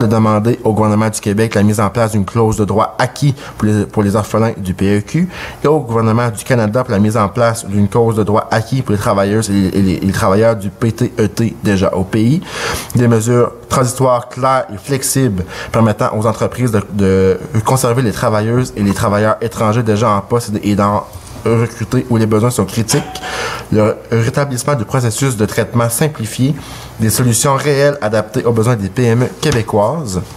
Le préfet du Rocher-Percé, Samuel Parisé :